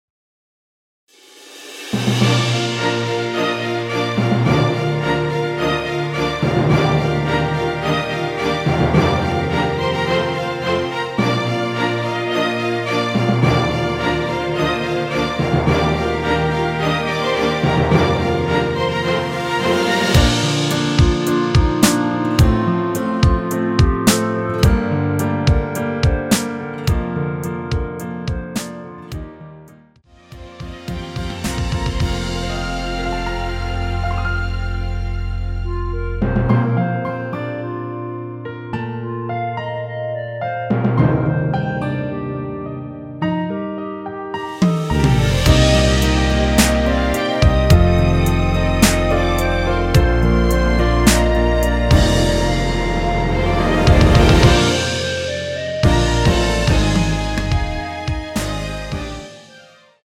원키에서(-2)내린 멜로디 포함된 MR입니다.(미리듣기 확인)
Bb
앞부분30초, 뒷부분30초씩 편집해서 올려 드리고 있습니다.